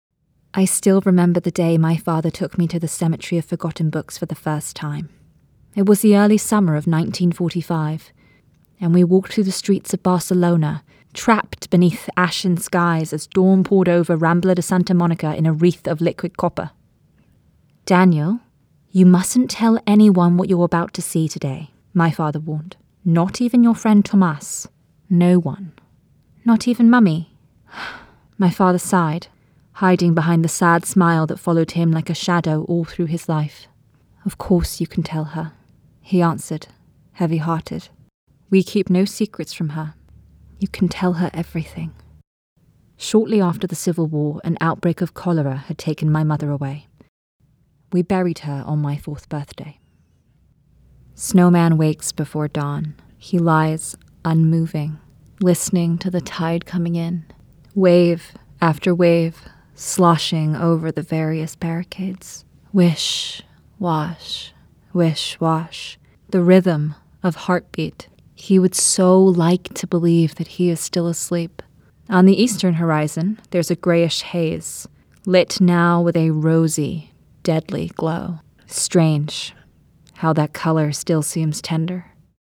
Playing age: Teens - 20s, 20 - 30s, 30 - 40sNative Accent: American, RPOther Accents: American, Australian, Estuary, International, London, RP, Yorkshire
• Native Accent: American Standard, RP
• Home Studio